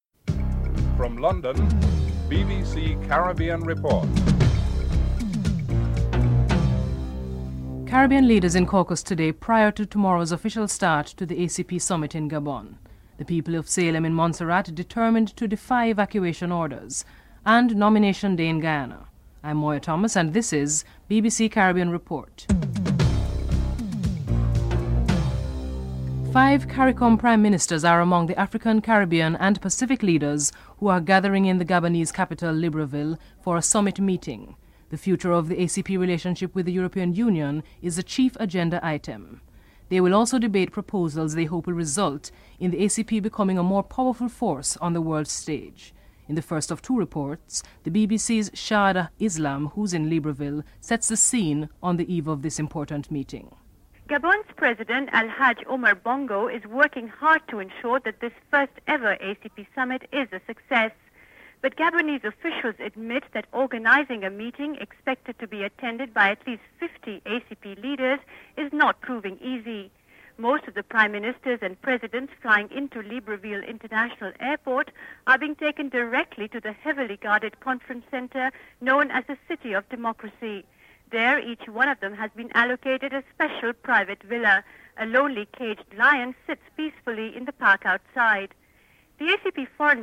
President Bill Clinton is interviewed (10:53-12:01)